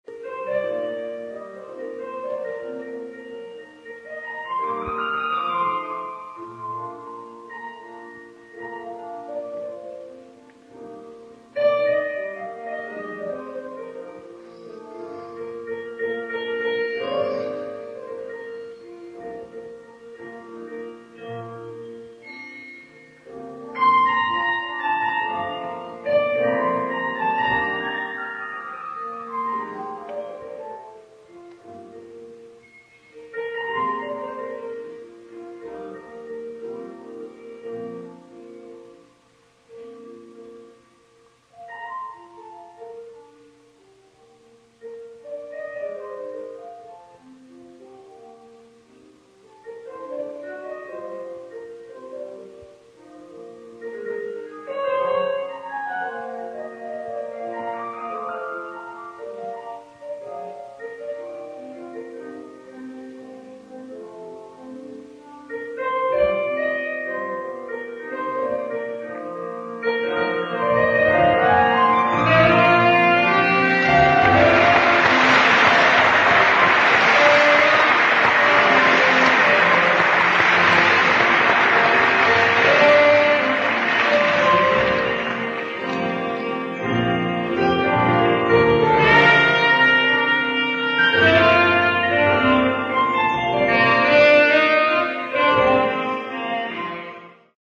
ライブ・アット・エイブリー・フィッシャー・ホール、ニューヨーク 07/02/1974
※試聴用に実際より音質を落としています。